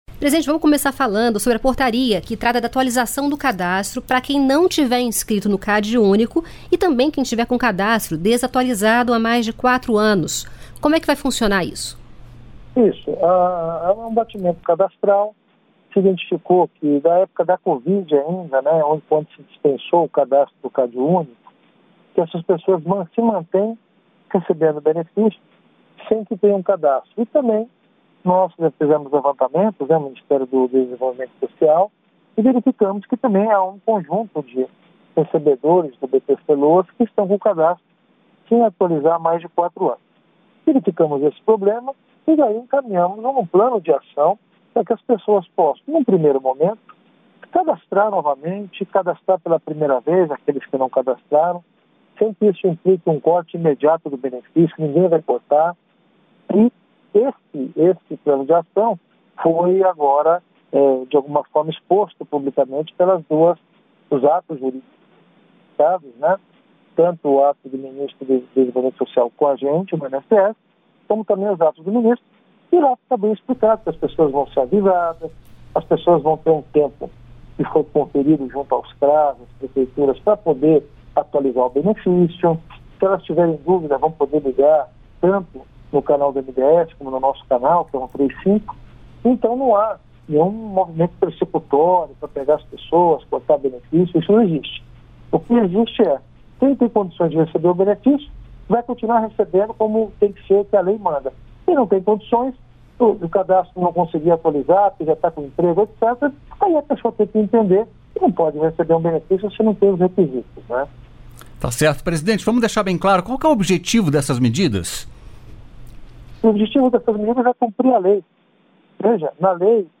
Entrevista com Alessandro Stefanutto, presidente do INSS